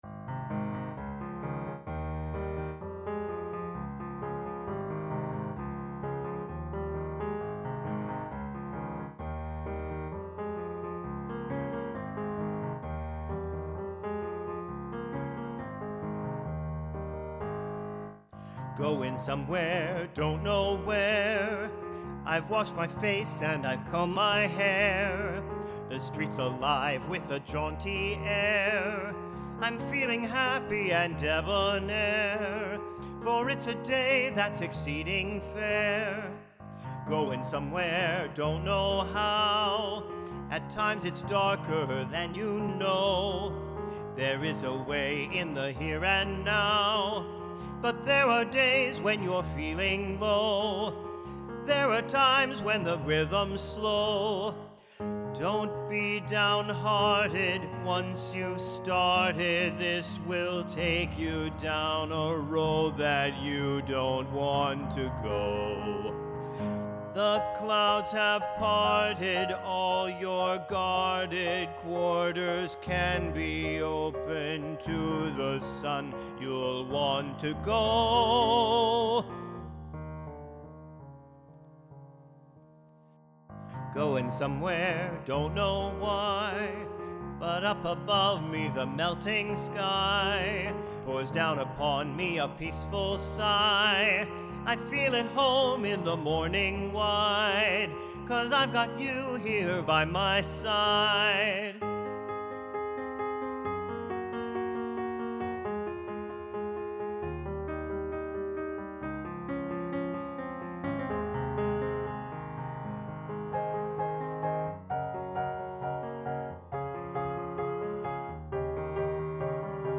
Goin' Somewhere (Vocals